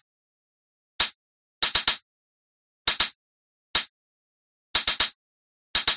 hi hat 14
描述：hi hat
Tag: 镲片 hi_hat Rides